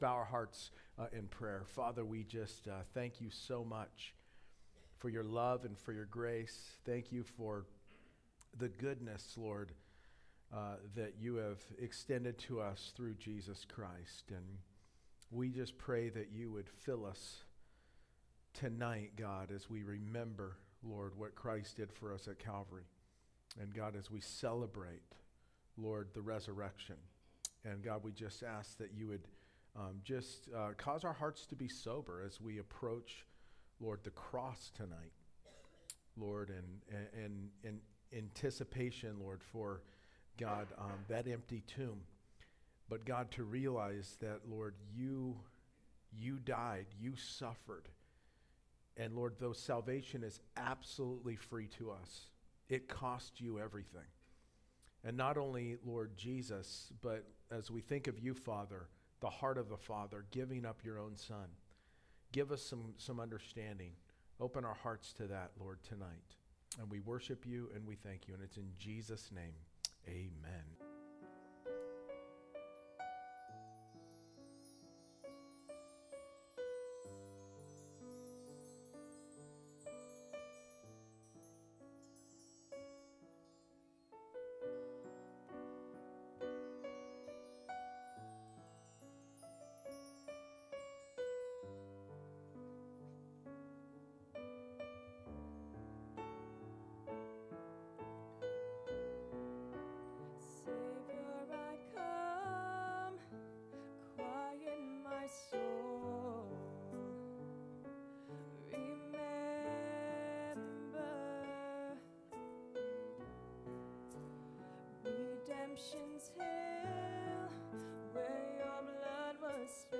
Isaiah 53 Good Friday Service